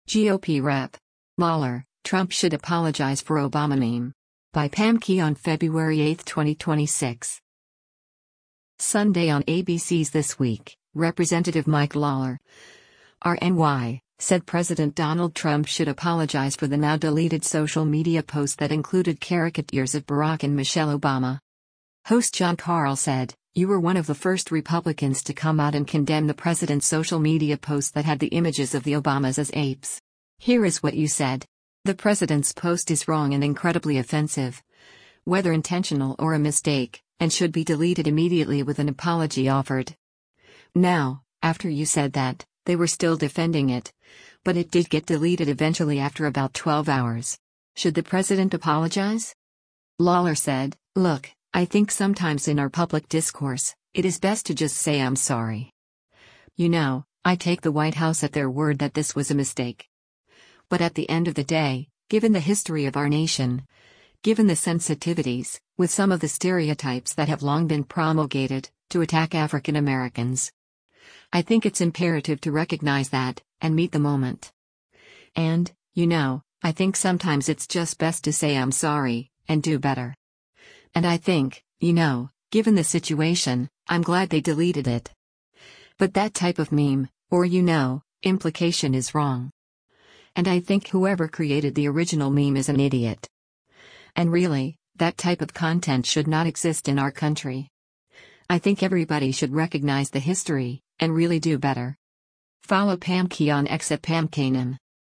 Sunday on ABC’s “This Week,” Rep. Mike Lawler (R-NY) said President Donald Trump should apologize for the now-deleted social media post that included caricatures of Barack and Michelle Obama.